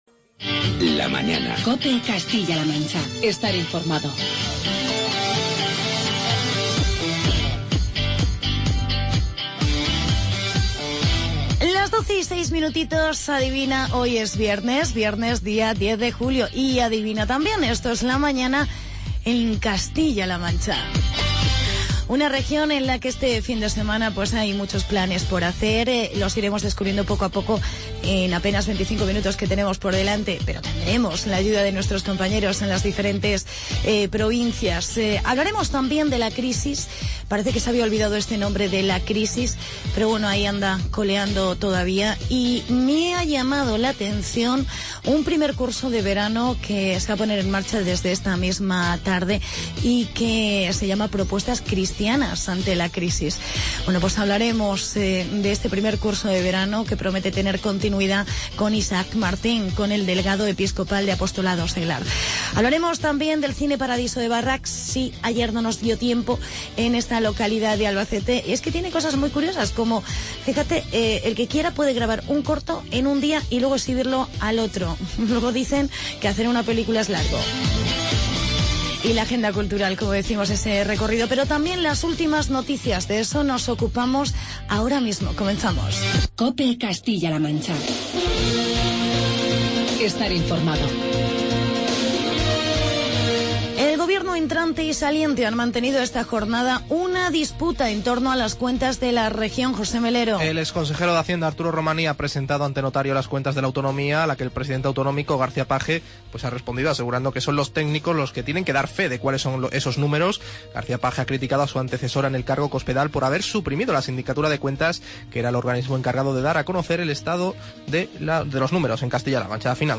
Reportaje Festival Barrax y Agenda cultural.